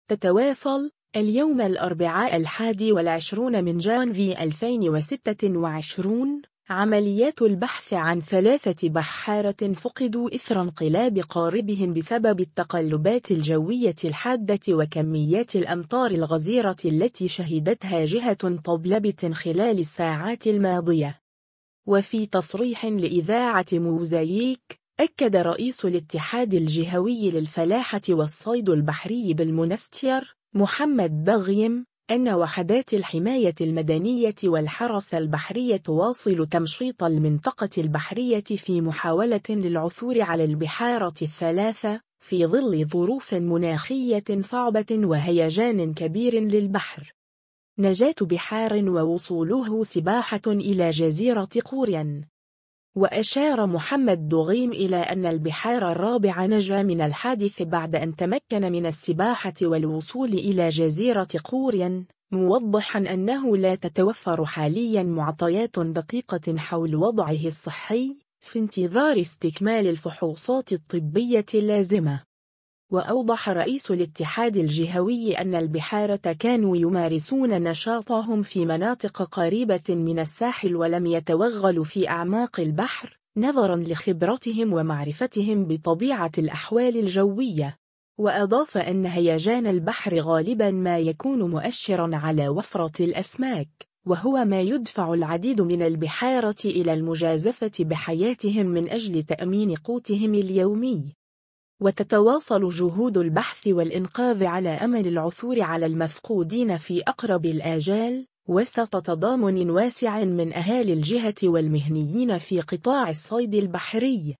وفي تصريح لإذاعة موزاييك